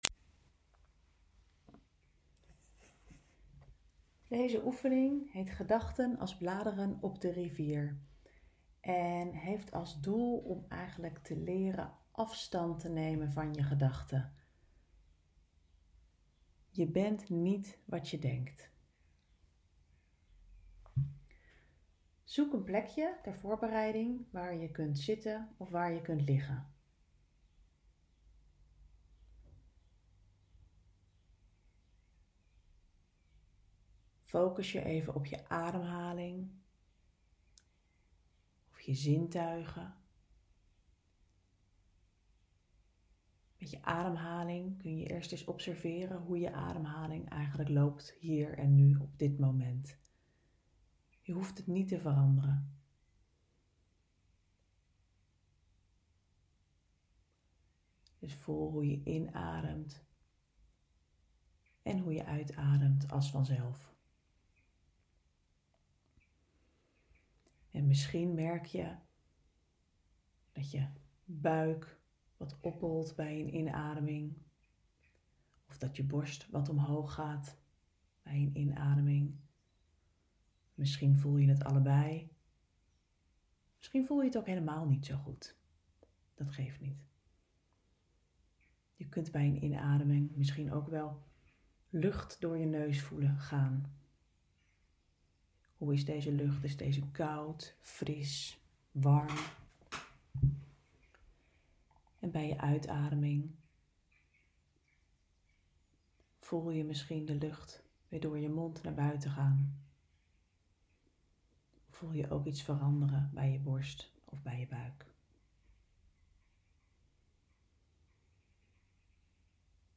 Met praktische ACT-oefeningen, een geleide audio-oefening (mp3), en hoe je ACT toepast in de natuur.